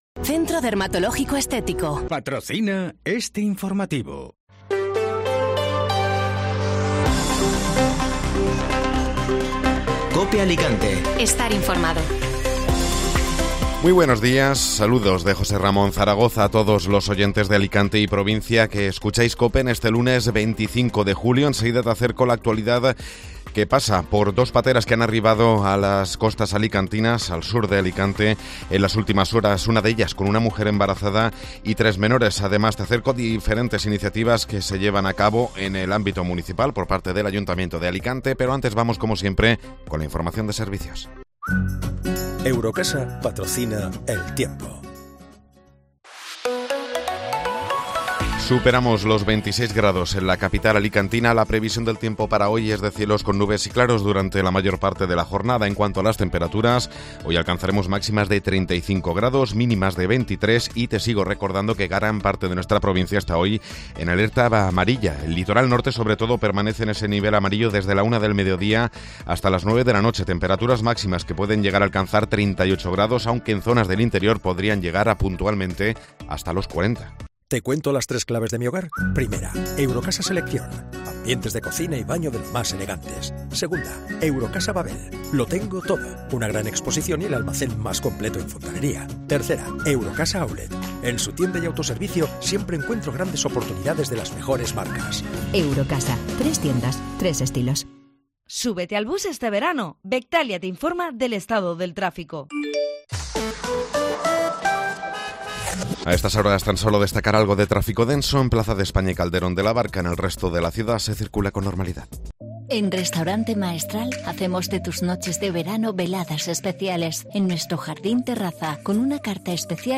Informativo matinal (Lunes 25 de Julio)